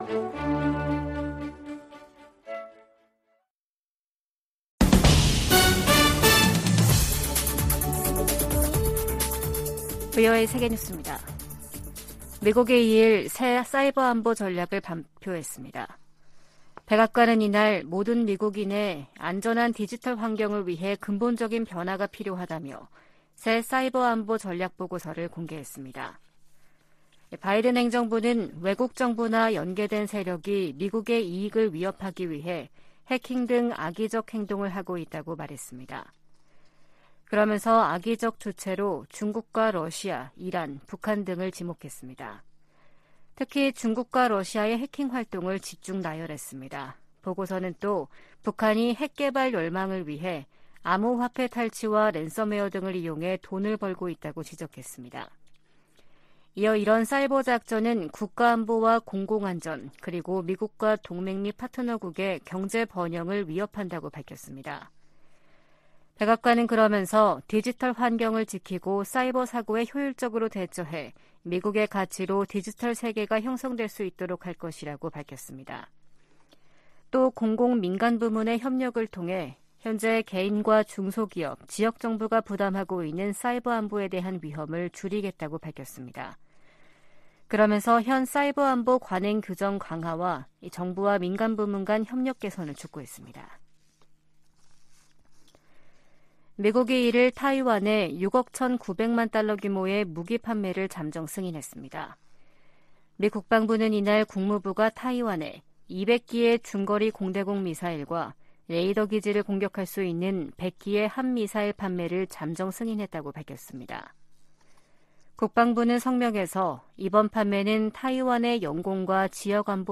VOA 한국어 아침 뉴스 프로그램 '워싱턴 뉴스 광장' 2023년 3월 3일 방송입니다. 미 국무부는 윤석열 한국 대통령의 3∙1절 기념사가 한일관계의 미래지향적 비전을 제시했다며 환영의 뜻을 밝혔습니다. 최근 실시한 미한일 탄도미사일 방어훈련이 3국 협력을 증진했다고 일본 방위성이 밝혔습니다. 미 하원에 한국전쟁 종전 선언과 평화협정 체결, 미북 연락사무소 설치 등을 촉구하는 법안이 재발의됐습니다.